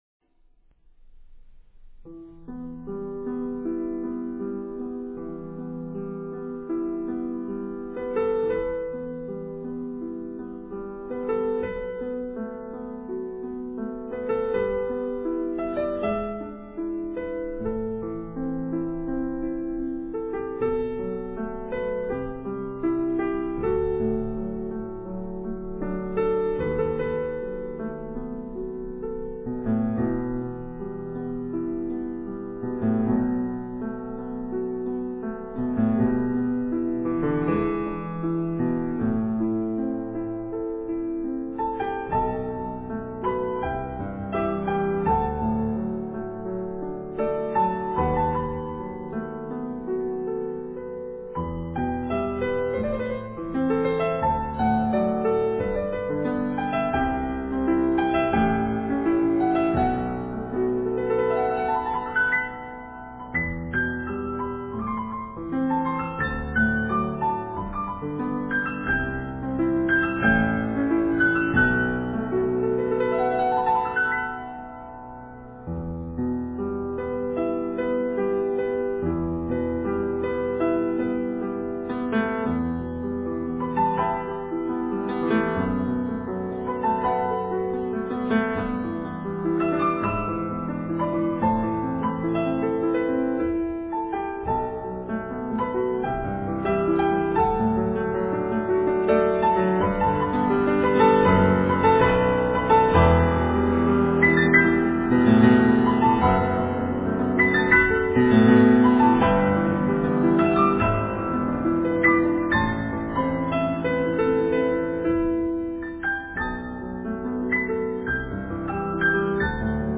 Mongolisches Lied